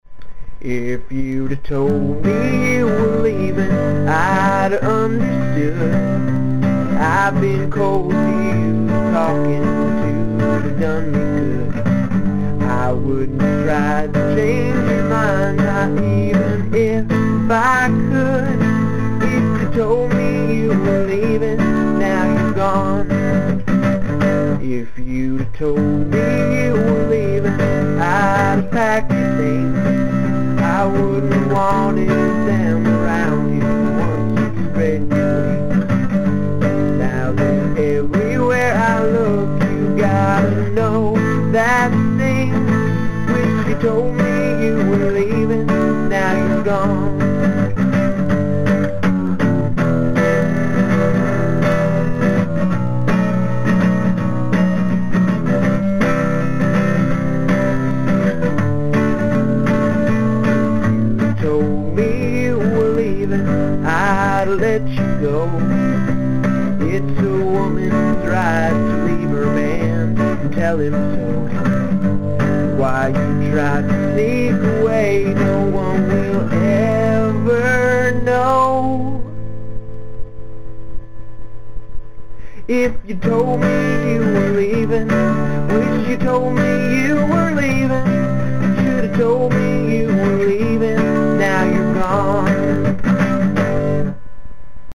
in G, easy